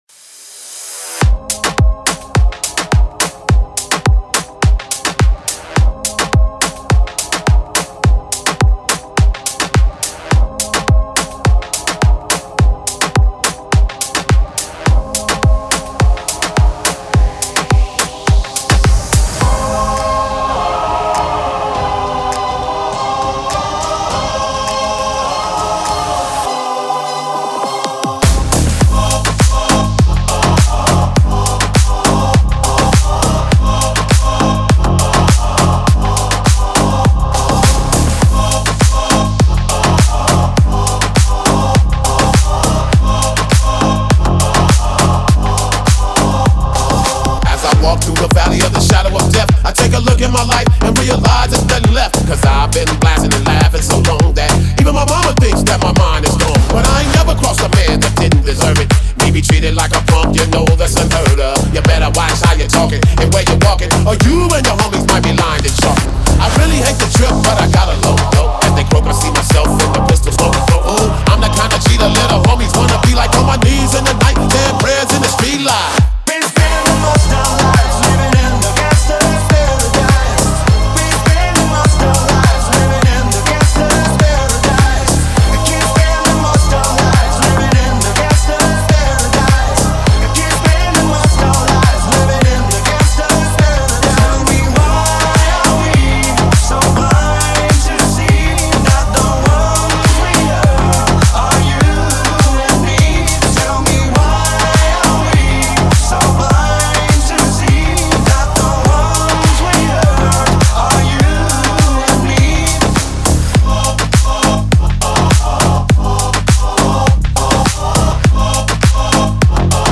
клубные ремиксы